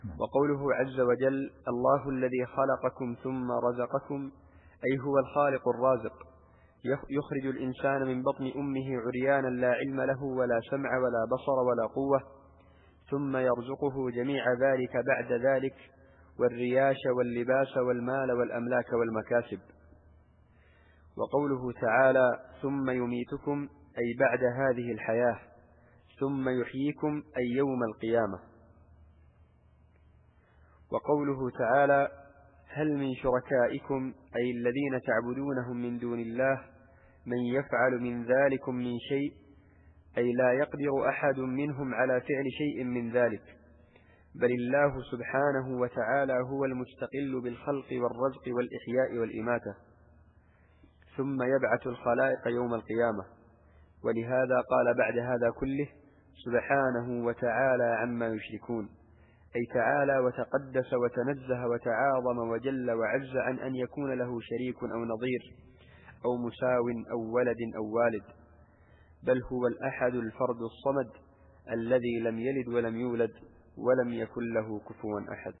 التفسير الصوتي [الروم / 40]